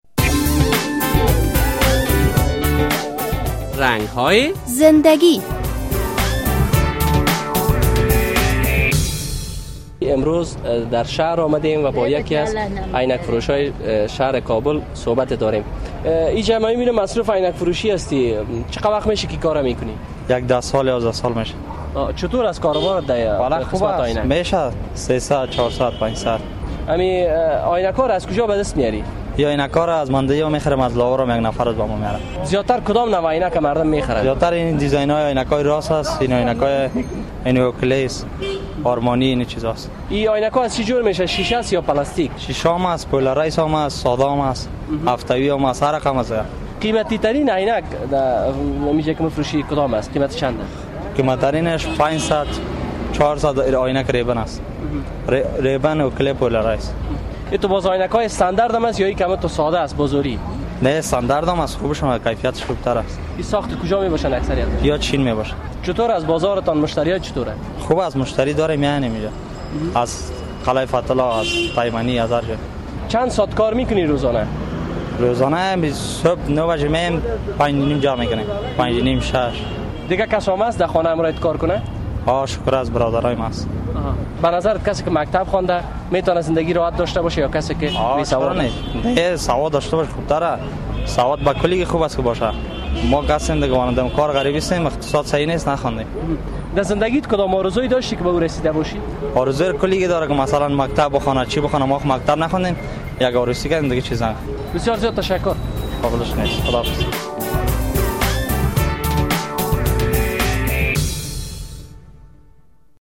یک تن از باشنده های شهر کابل که مدت بیشتر از ده سال می شود عینک فروشی می کند در مصاحبه با خبرنگار برنامهء رنگ های زنده گی گفت که روزانه دو صد الی سه صد افغانی از درک فروش عینک عاید دارد و از کارش راضی است...